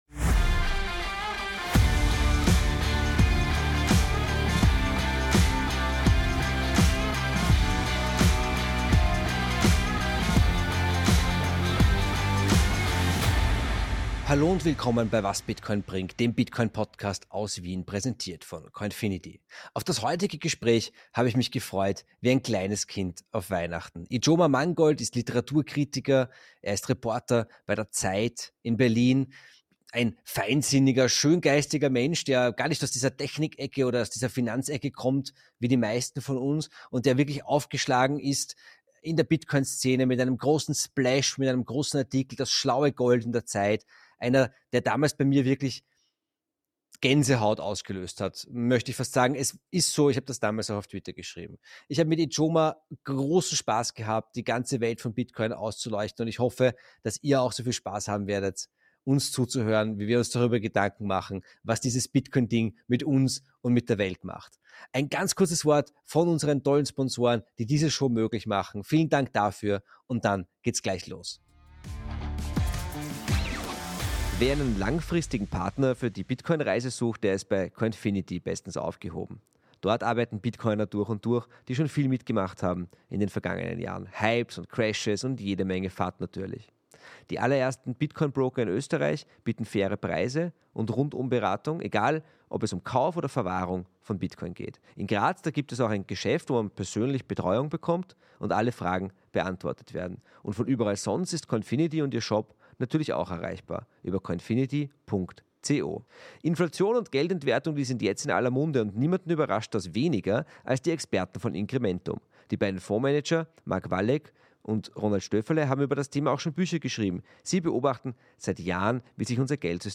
Auf dieses Interview habe ich mich lange gefreut.